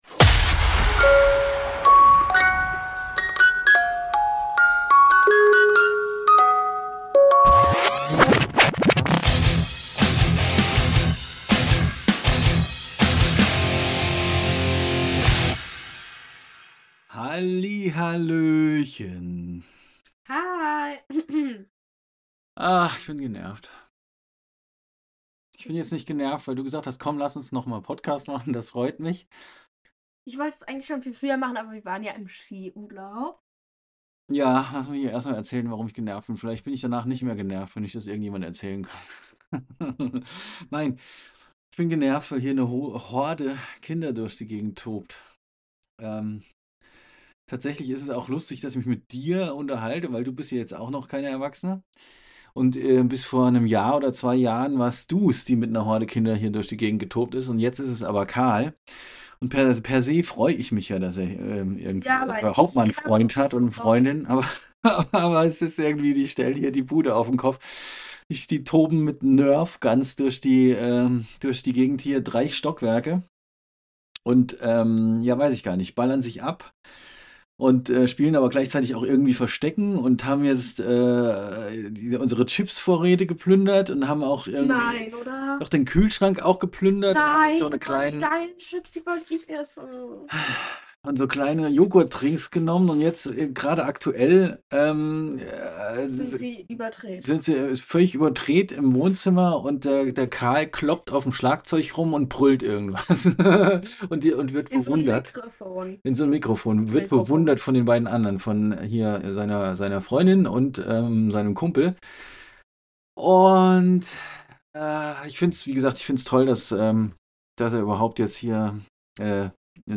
Von grottigem Ton und Hundewelpen im Schnee